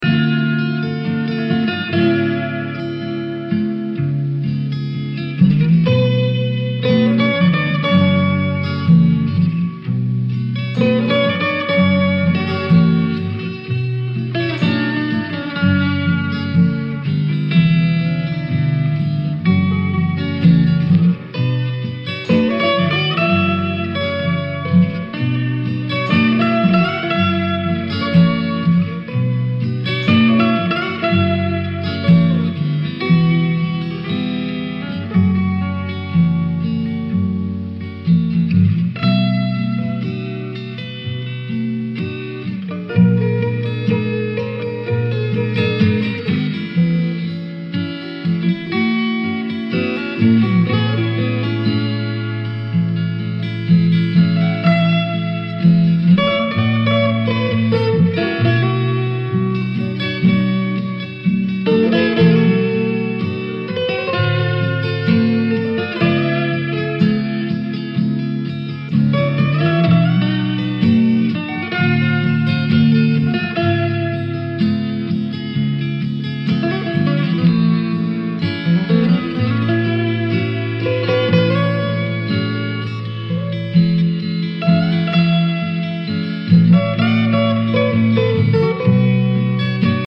フォークを中心にダブやバレアリック的なサウンドをも展開する極上作品！